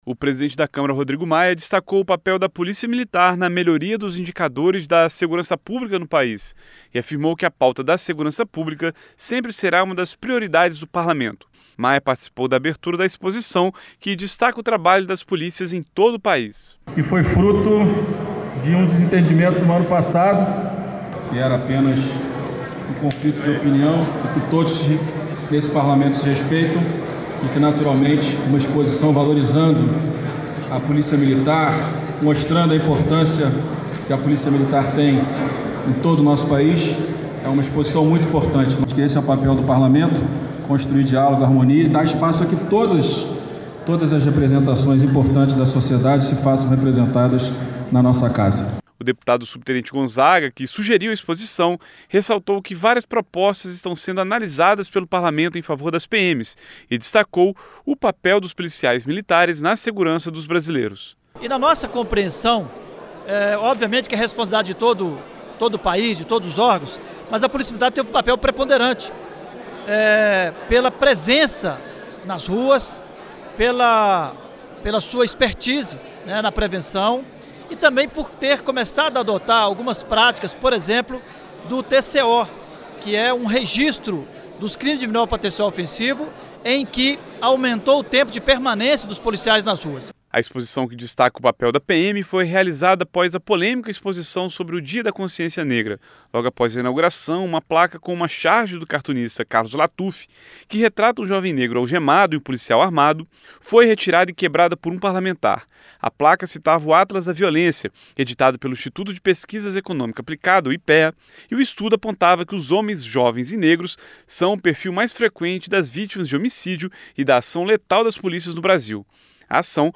• Áudio da matéria